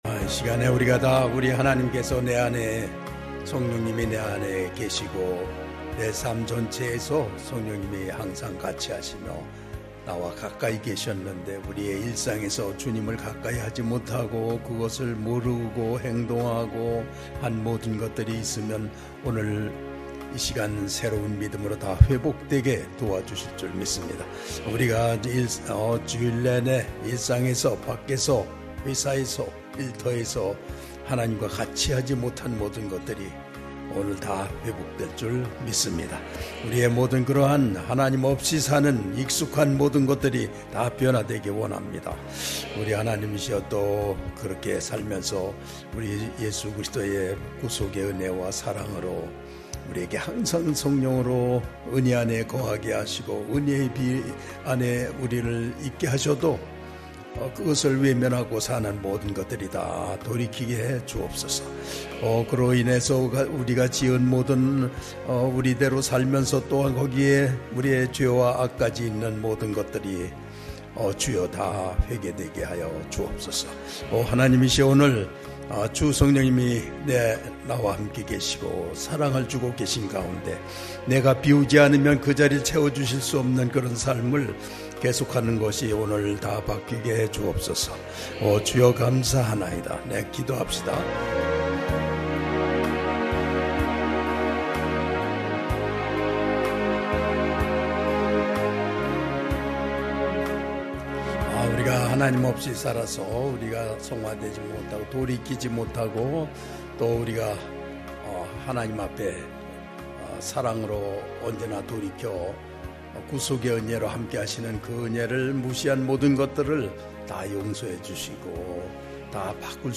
수요가족예배